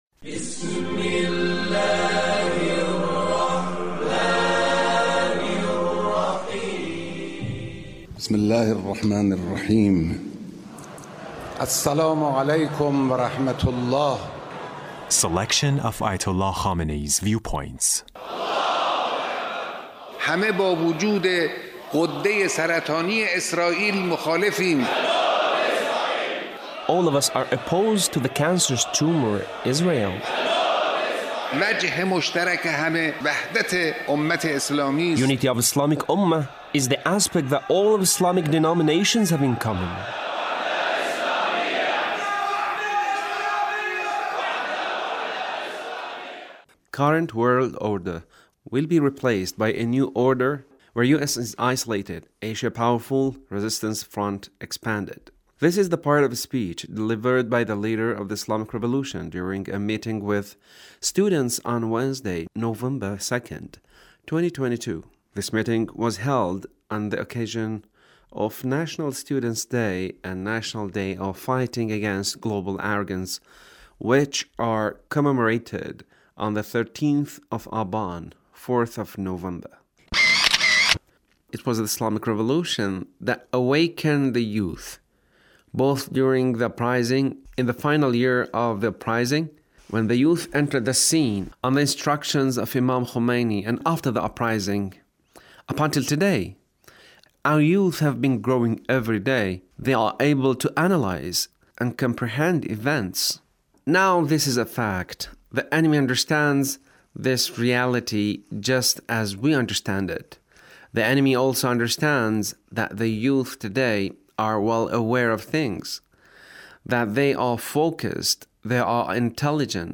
Leader's Speech (1579)
Leader's Speech on 13th of Aban